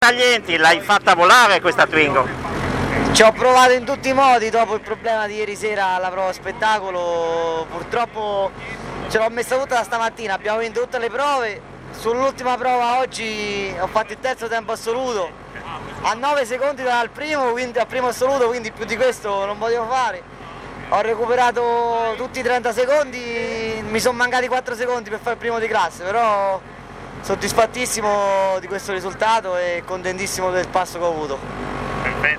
Interviste Rally di Cassino / Pico
Interviste di fine gara